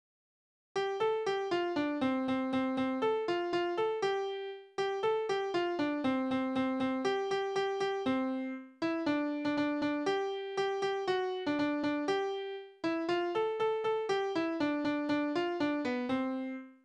Tonart: C-Dur
Taktart: 4/4
Tonumfang: kleine Septime